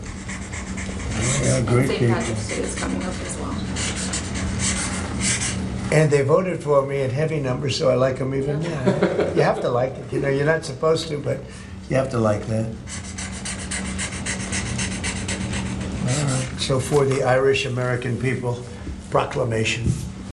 While signing the proclamation last night, he called Irish-Americans “great people………………